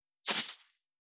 383,ふりかけ,サラサラ,パラパラ,ジャラジャラ,サッサッ,ザッザッ,シャッシャッ,シュッ,パッ,
効果音厨房/台所/レストラン/kitchen
効果音